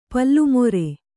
♪ plallu more